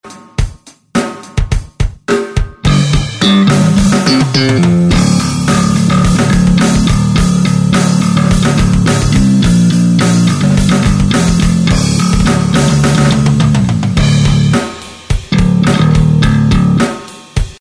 вот сэмпл автору топика, правда дико кривой, пальцами 3-й день играю, до этого только медиатором...
Настройки на BD30B
Bass 9
Middle 0
Treble 5
Contour 9
Снимал Shure-ом SM58
basscombo.mp3